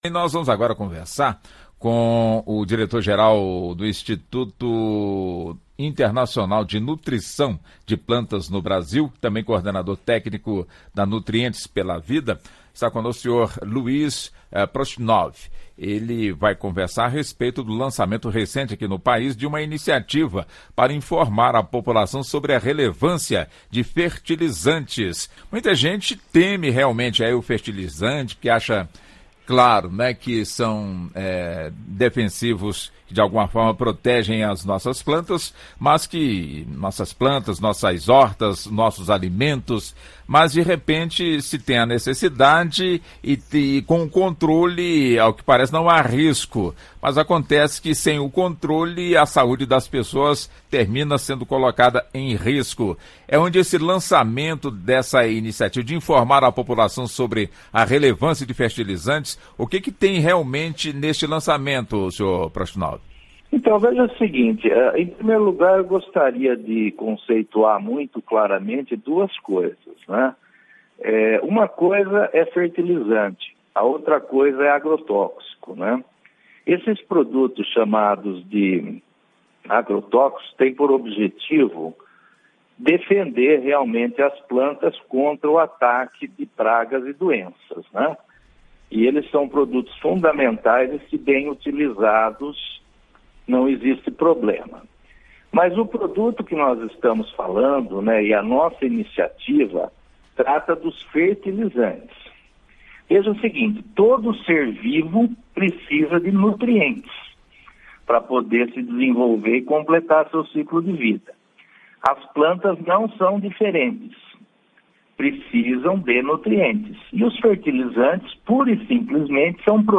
Entrevista: Saiba mais sobre fertilizantes